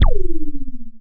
gate_close.wav